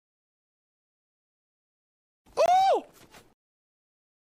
Myinstants > Звуки > Reactions > Ohhhhh!